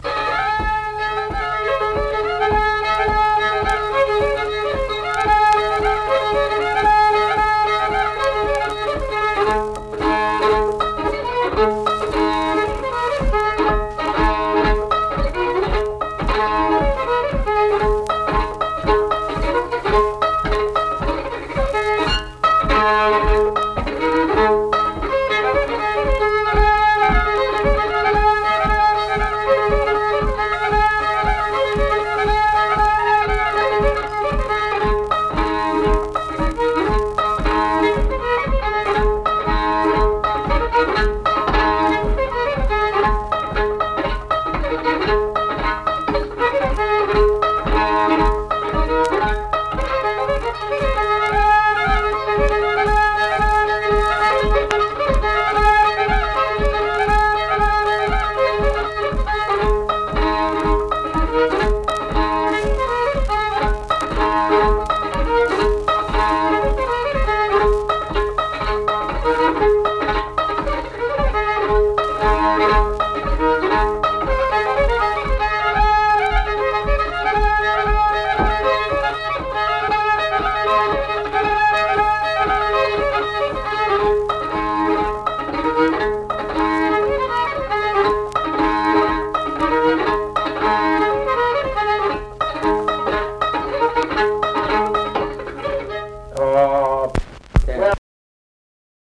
Unaccompanied fiddle music
Verse-refrain 8(2).
Instrumental Music
Fiddle
Pocahontas County (W. Va.), Mill Point (W. Va.)